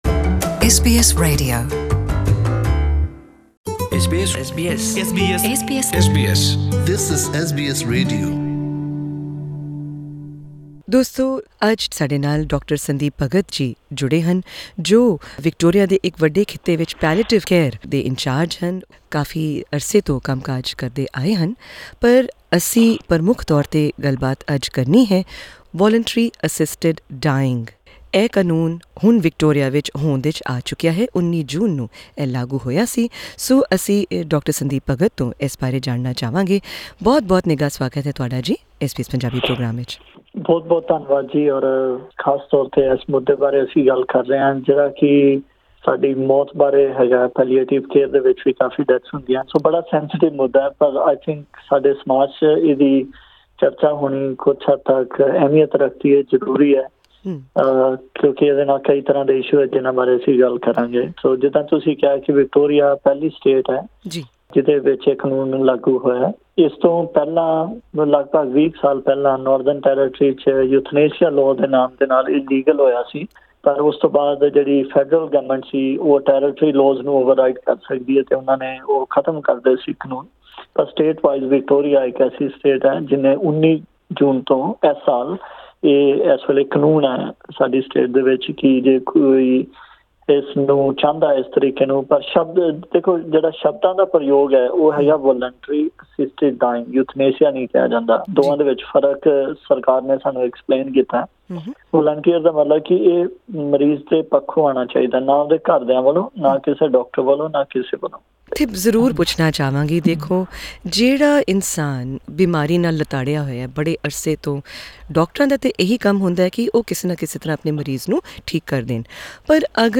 In this interview, he shines a light on all the nuances of the new law, like: Who is 'eligible' to opt for VAD?